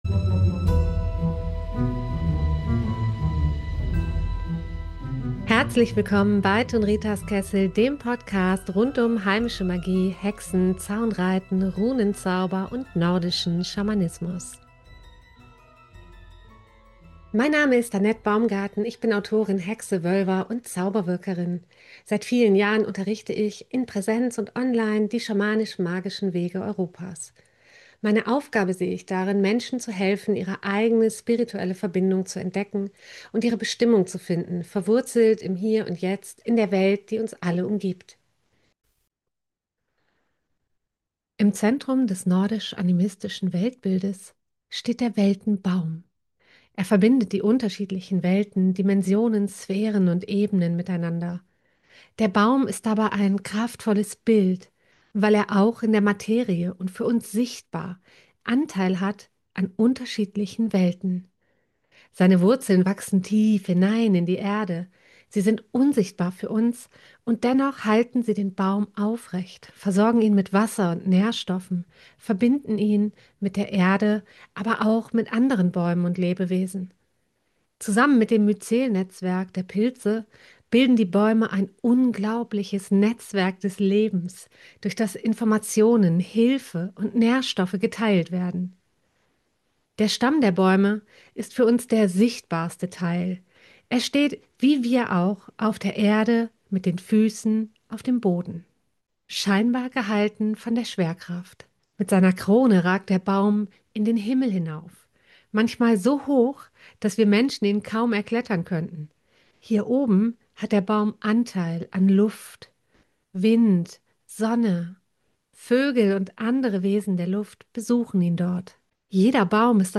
Geführte Weltenbaum-Meditation, die dich zwischen Himmel und Erde verankert, deine Kraft stärkt und einen schützenden Raum um dich webt.